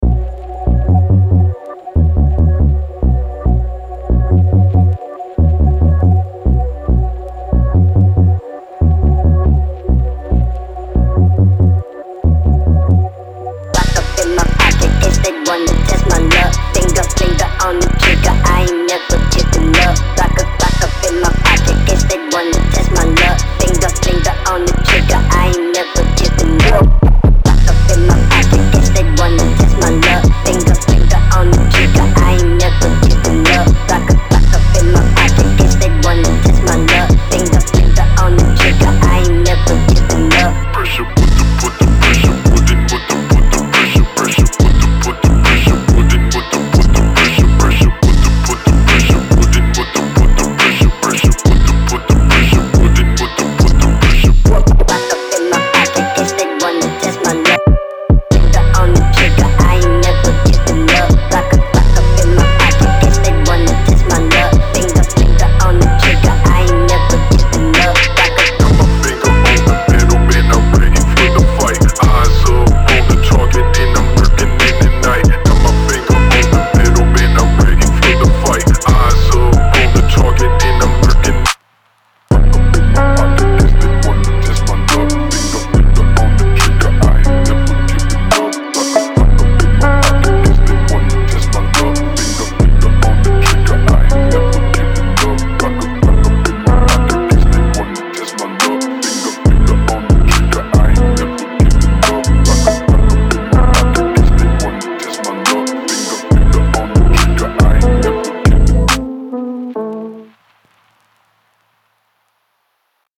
Трек размещён в разделе Зарубежная музыка / Фонк.